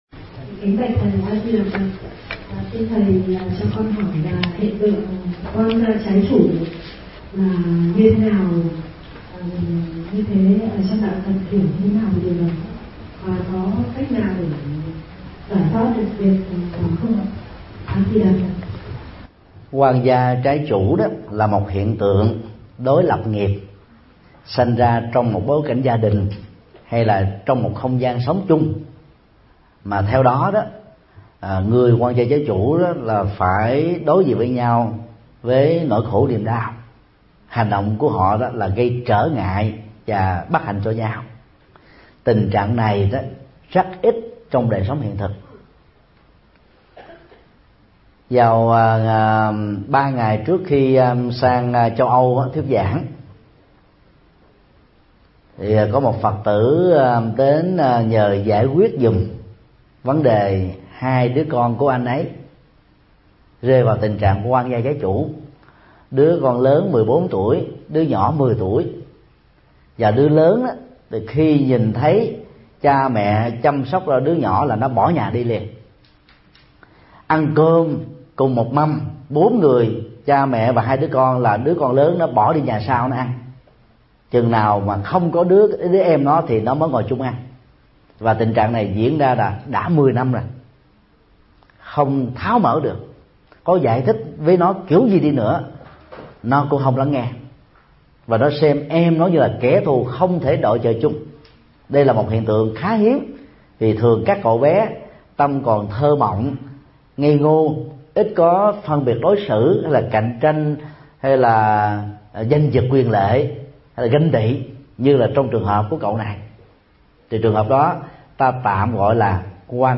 Vấn đáp: Hiện tượng oan gia trái chủ